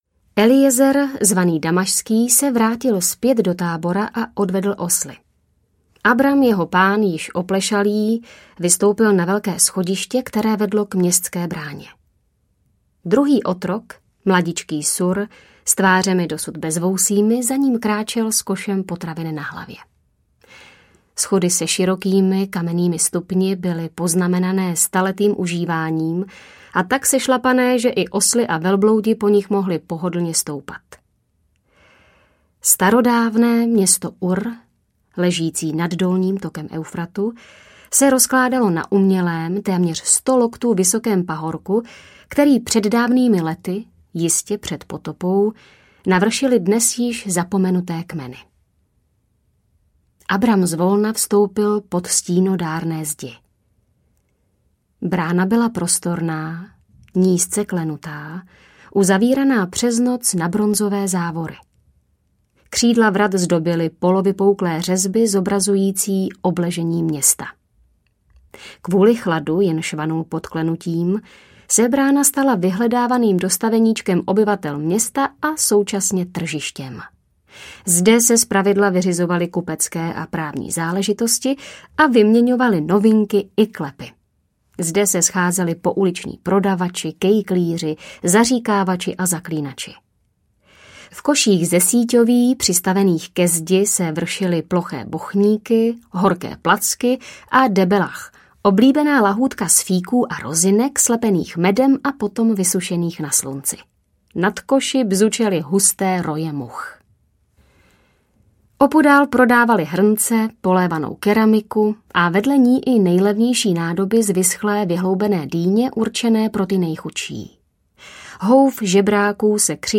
Úmluva - Příběh o Abrahámovi audiokniha
Ukázka z knihy
umluva-pribeh-o-abrahamovi-audiokniha